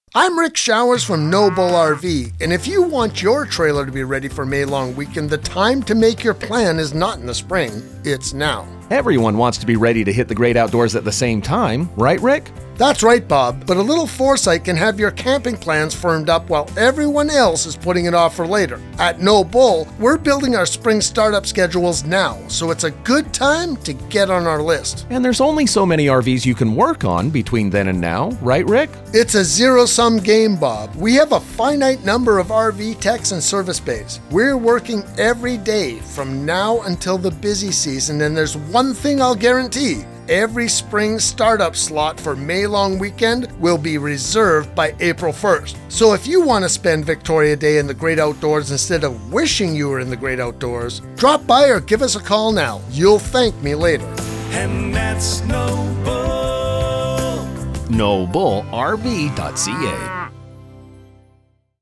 2026 Radio Ads